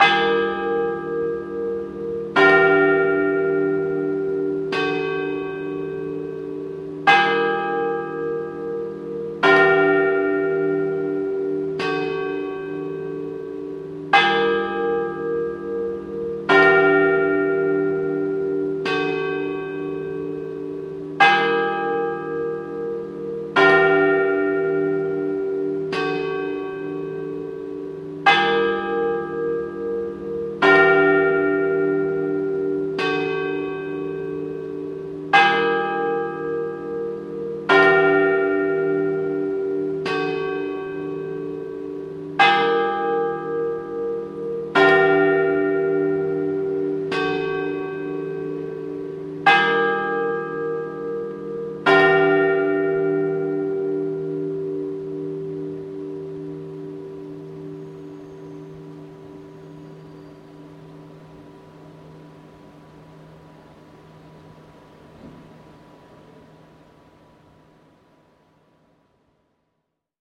На этой странице собраны мрачные и атмосферные звуки, связанные с трупами: от приглушенных стонов до леденящих душу шорохов.
Звон колокола, возвещающий о кончине человека